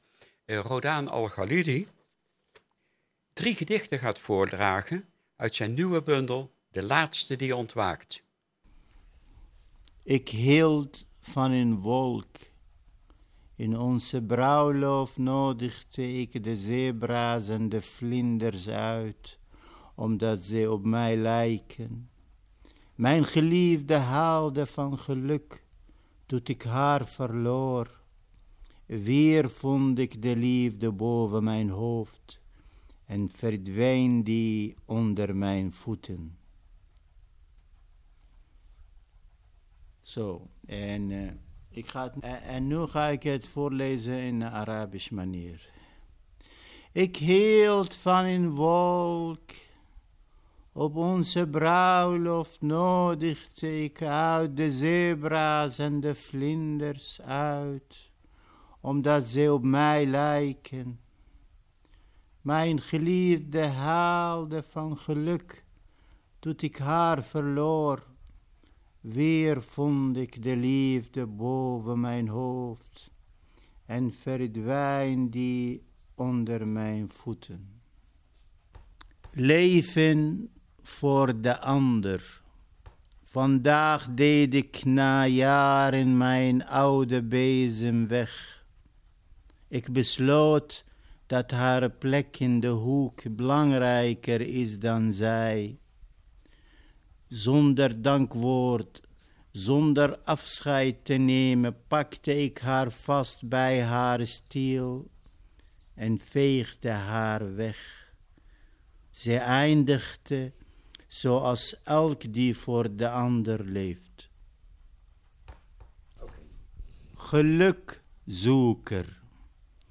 Rodaan Al Galidi leest drie gedichten: